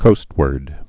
(kōstwərd)